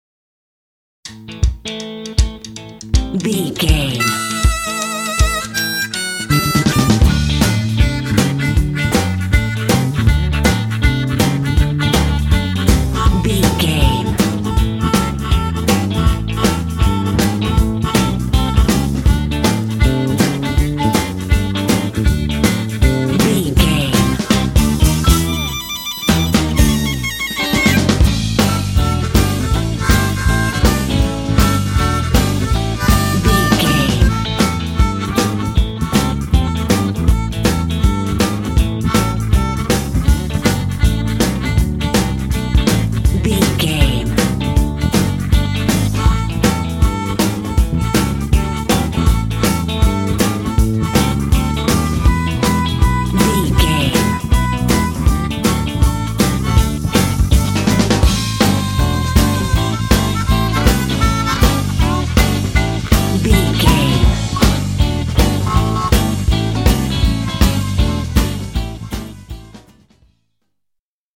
Aeolian/Minor
happy
bouncy
groovy
drums
bass guitar
acoustic guitar
americana